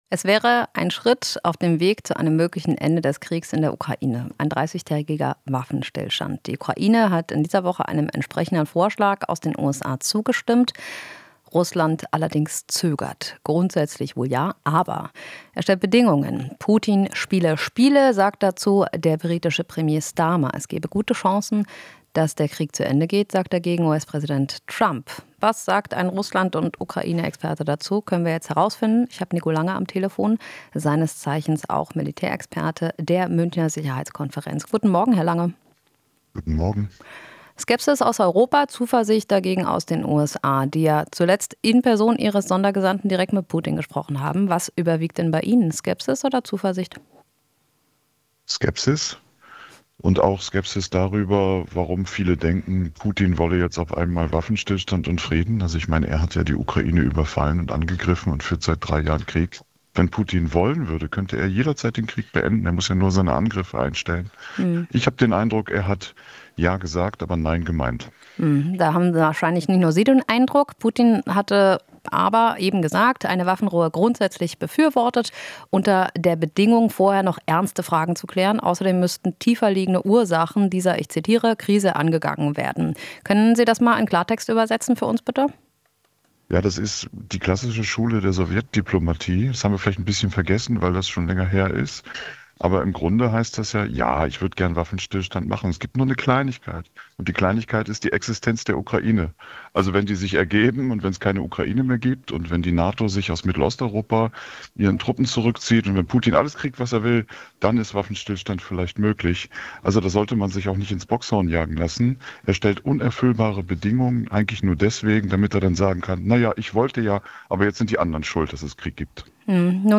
Interview - Politologe zur Ukraine: Trumps nächster Schritt ist entscheidend